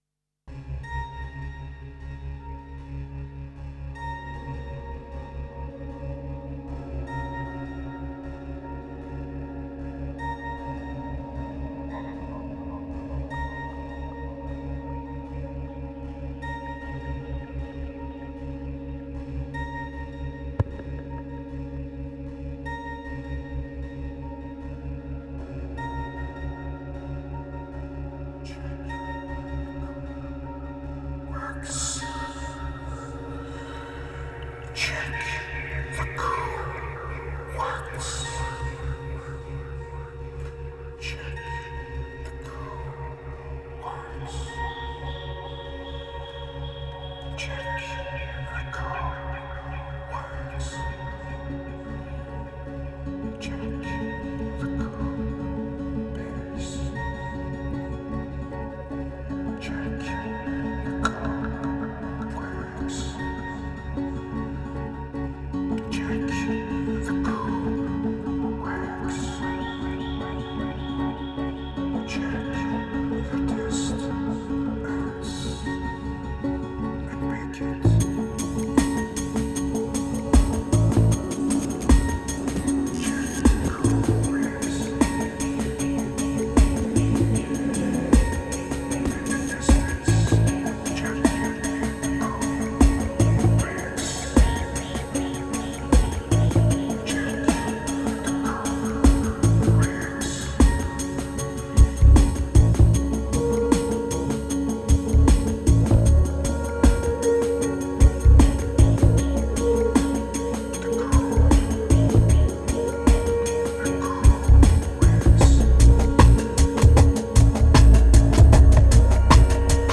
2555📈 - 85%🤔 - 77BPM🔊 - 2011-11-27📅 - 345🌟